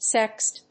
/sɛkst(米国英語), sekst(英国英語)/